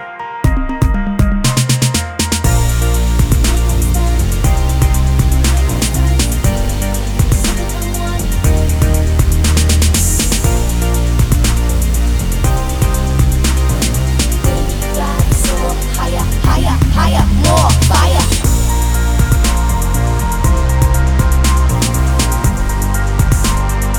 no Backing Vocals R'n'B / Hip Hop 3:36 Buy £1.50